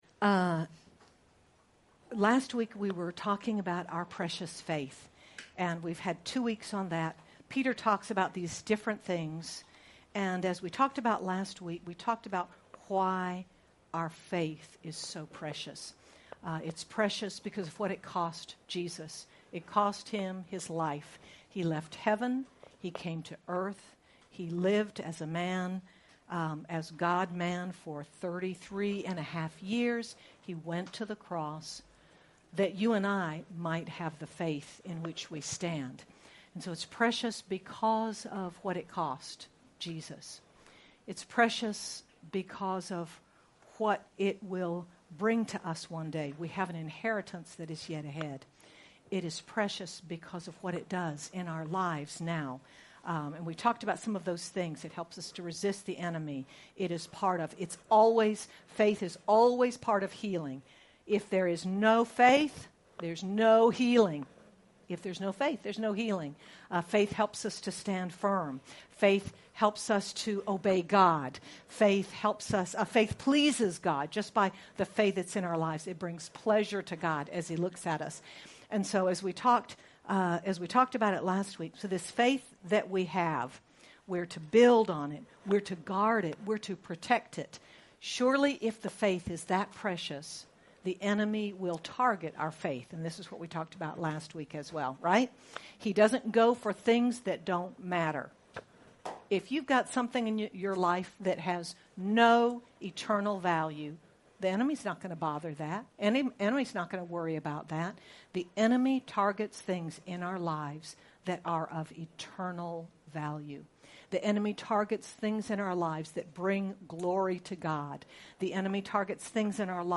Feb 13, 2024 Jesus the Messiah MP3 SUBSCRIBE on iTunes(Podcast) Notes Discussion This message begins our Lent series on Jesus the Messiah from the Gospel of Matthew. We see Jesus, the anointed one, sent by the Father, to bring God’s kingdom to our lives. Sermon by